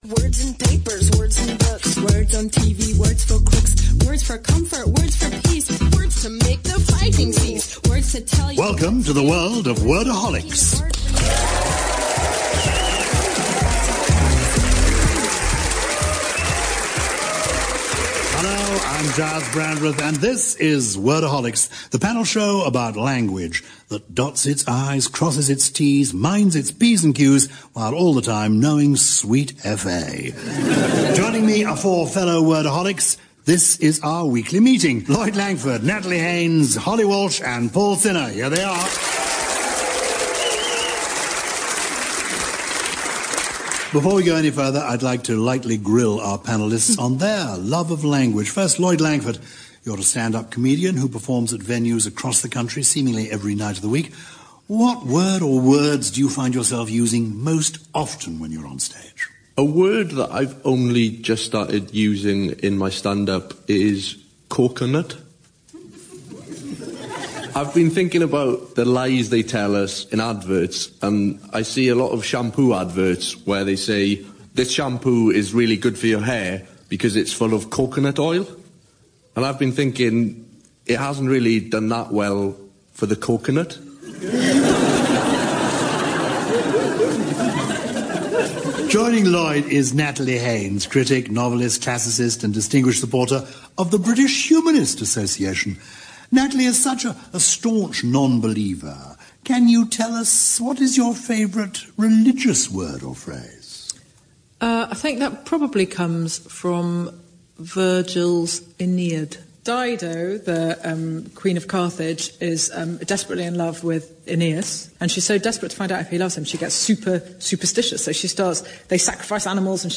An archive of the comedy quiz show Wordaholics